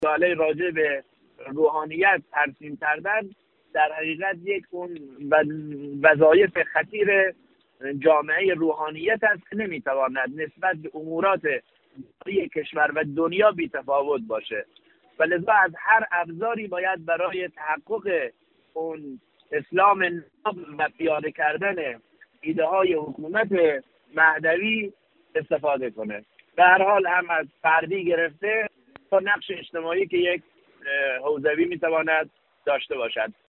حجت الاسلام سیدسجاد موسوی، امام جمعه الشتر، در گفت و گو با خبرنگار خبرگزاری رسا در خرم آباد، به منشور روحانیت اشاره کرد و بیان داشت: در جامعه، روحانیت رسالت و وظیفه خطیری بر دوش دارد.